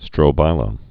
(strō-bīlə)